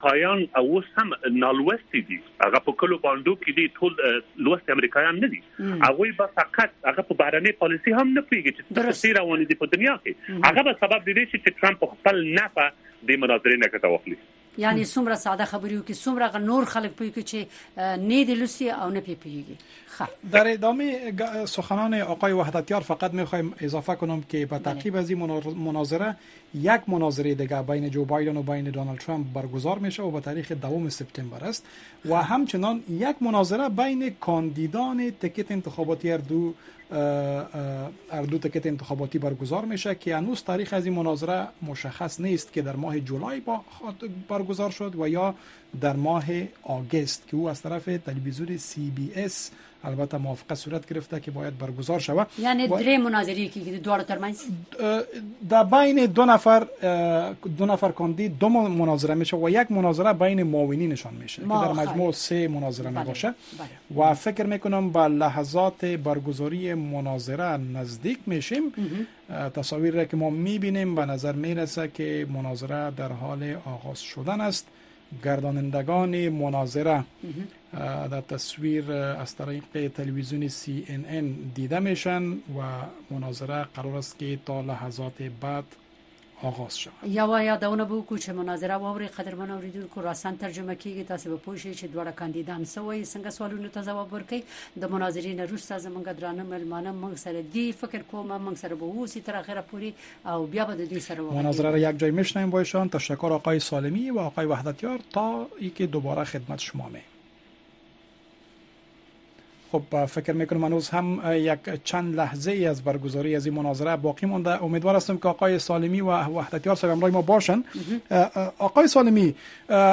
د متحده ایالاتو ولسمشرۍ ته نوماندانو جو بایډن او ډونالډ ټرمپ د سي ان ان د خبري شبکې په کوربتوب په لومړنۍ مناظره کې برخه اخیستې ده.